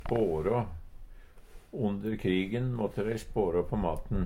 DIALEKTORD PÅ NORMERT NORSK spårå spare Infinitiv Presens Preteritum Perfektum spårå spårå spårå spårå Eksempel på bruk Onder krigen måtte dei spårå på maten.